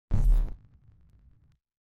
جلوه های صوتی
دانلود صدای ربات 45 از ساعد نیوز با لینک مستقیم و کیفیت بالا